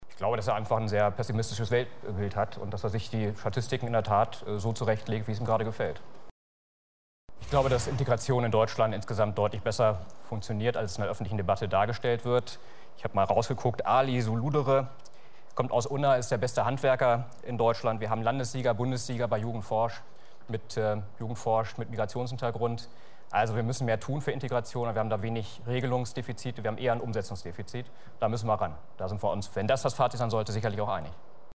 O-Ton-Paket Sarrazin (frei bei Nennung der Quelle SAT.1 Talksendung “Eins gegen Eins”)